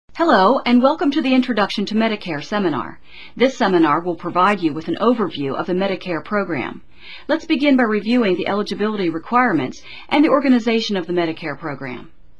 Select the Audio icon to hear the instructor begin the seminar or select the Text icon to read the script.